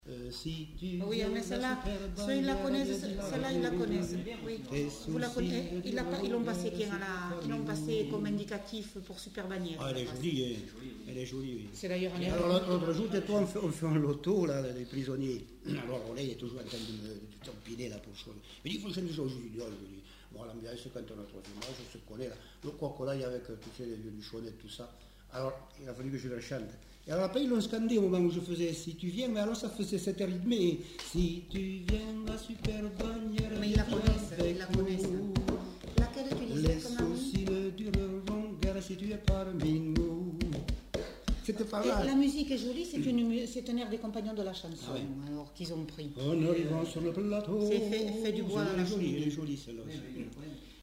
Lieu : Bagnères-de-Luchon
Genre : chant
Effectif : 1
Type de voix : voix d'homme
Production du son : chanté
Notes consultables : Entrecoupé de commentaires.